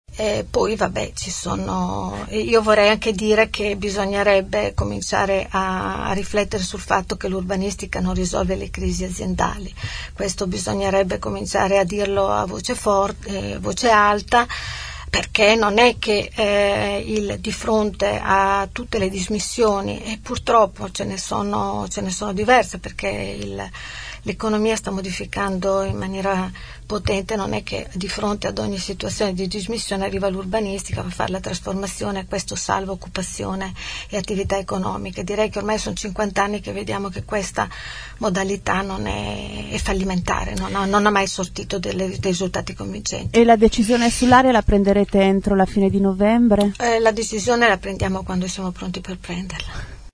5 nov. – Patrizia Gabellini, assessore all‘urbanistica e all’ambiente, ospite questa mattina nei nostri studi, descrive così il carattere di Bologna: “è di una lentezza spaventosa, discute tanto e fa fatica a convergere, è molto attaccata al passato e ha paura“.